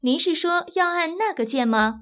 ivr-did_you_mean_to_press_key.wav